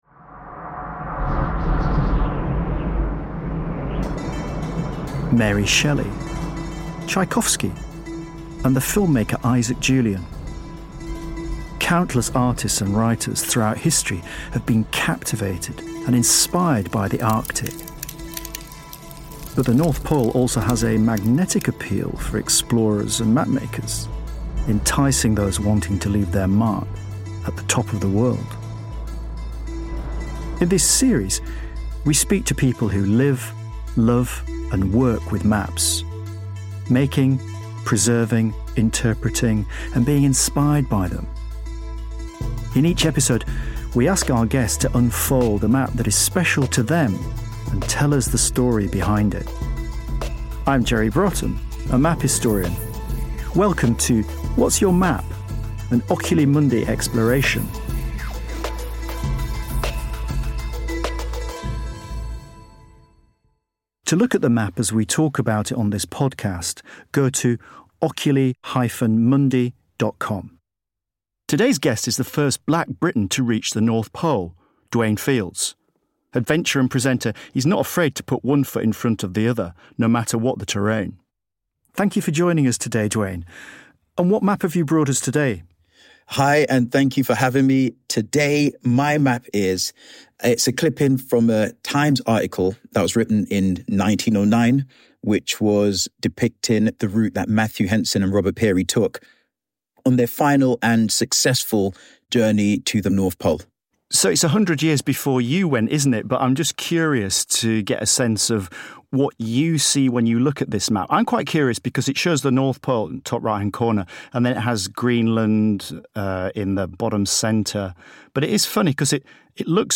In this second episode of What’s Your Map? host and map historian Jerry Brotton is joined by Dwayne Fields, the first Black Briton to reach the magnetic North Pole.